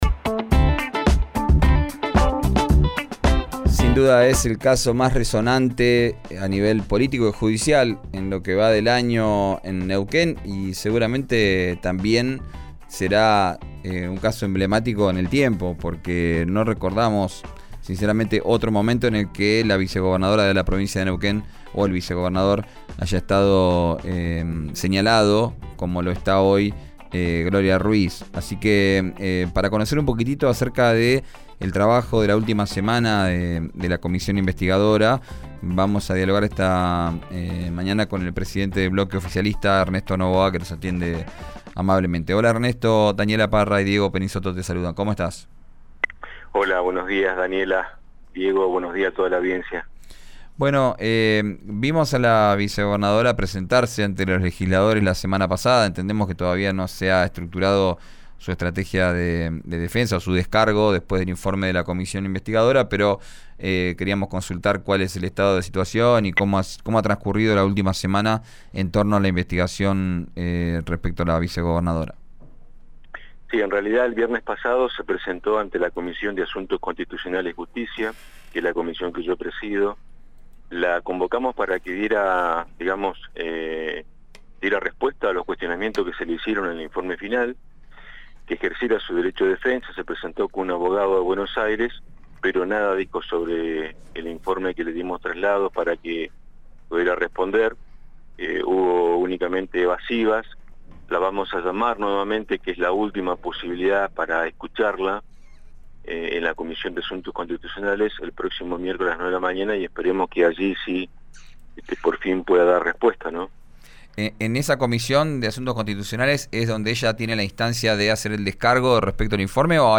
Escuchá al diputado de Neuquén, Ernesto Novoa (Comunidad), por RÍO NEGRO RADIO: